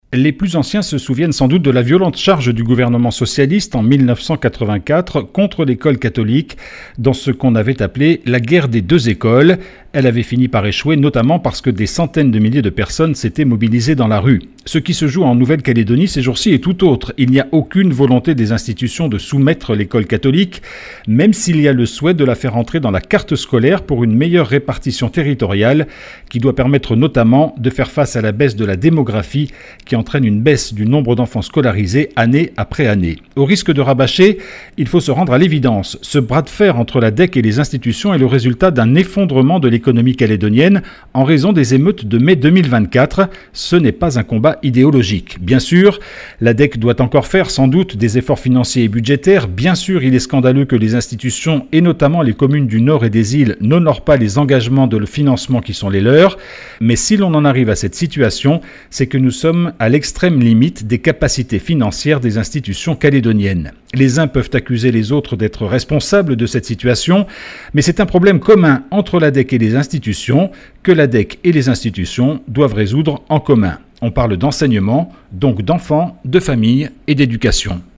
LE BILLET D'HUMEUR